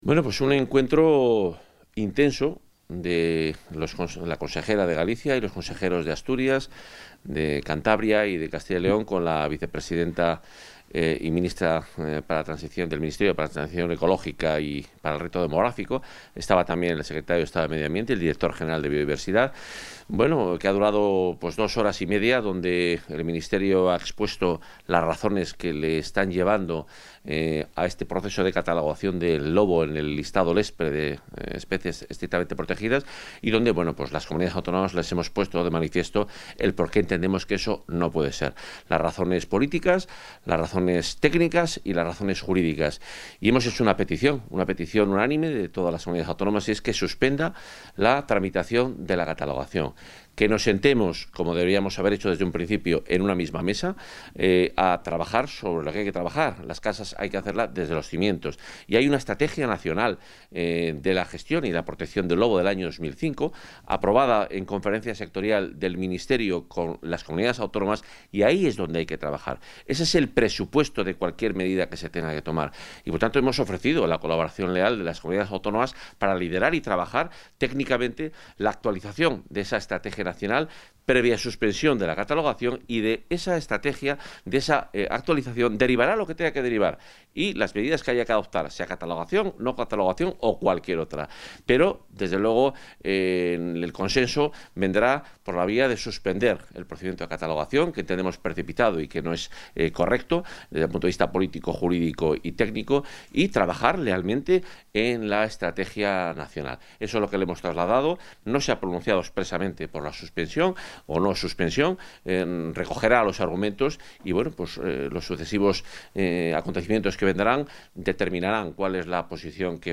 Valoración del consejero de Fomento y Medio Ambiente.